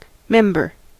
Ääntäminen
US : IPA : /ˈmɛm.bɚ/ UK : IPA : /ˈmɛm.bə/